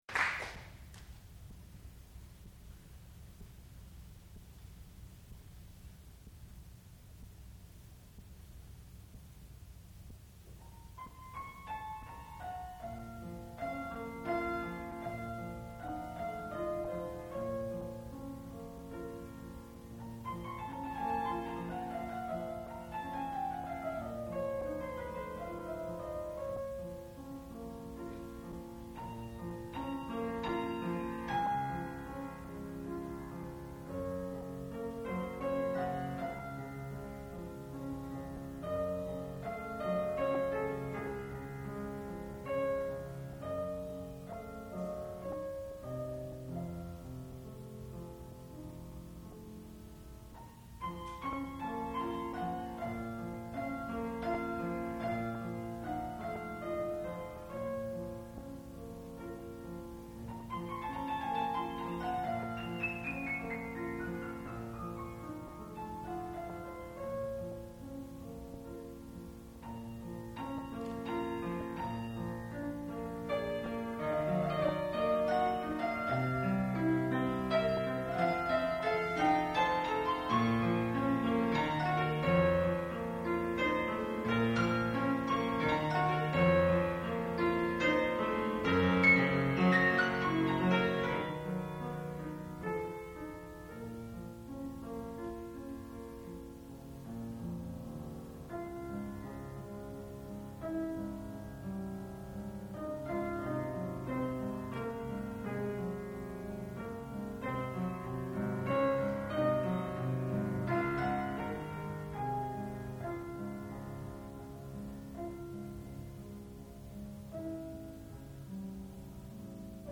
sound recording-musical
classical music
piano
Graduate Recital